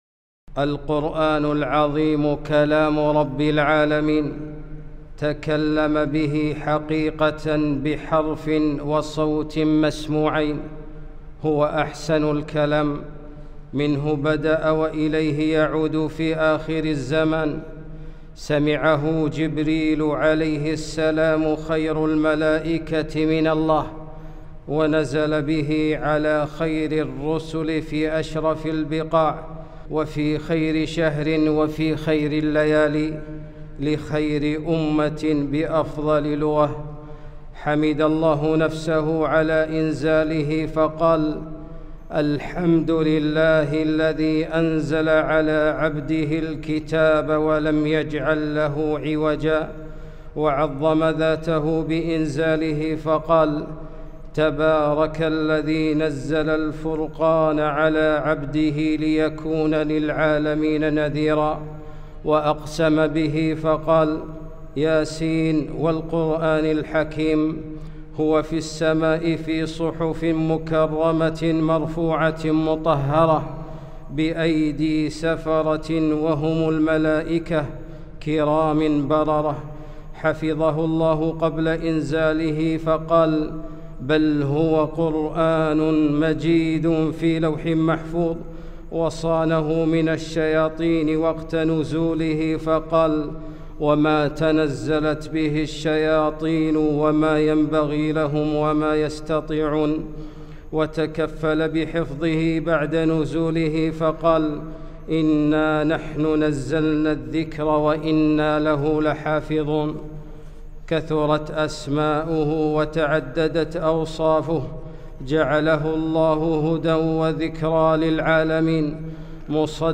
خطبة - أحسن الحديث وأفضله